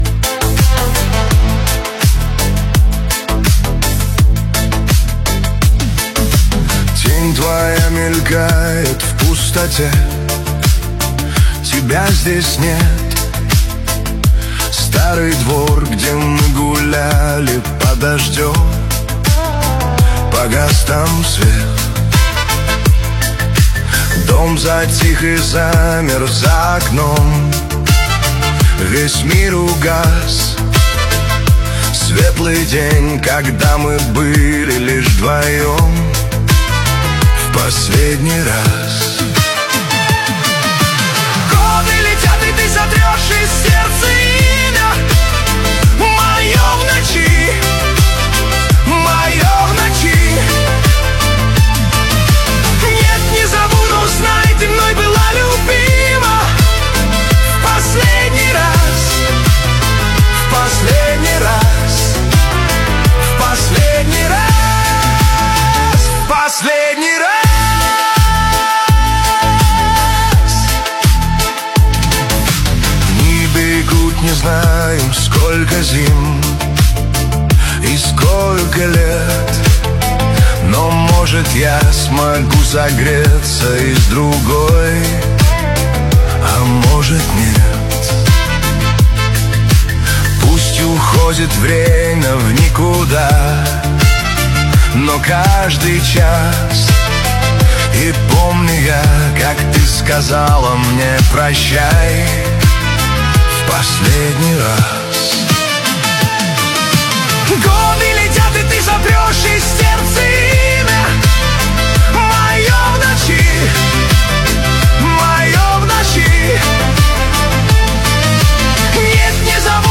Песни в мужском исполнении